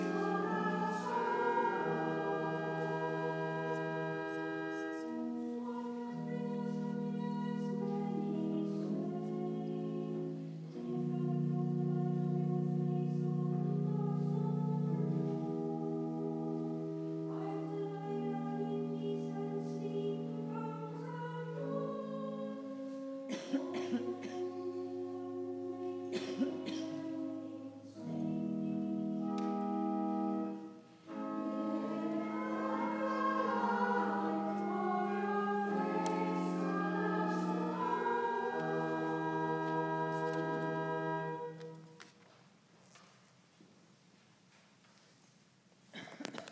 Responsorial Psalm
A bit too distant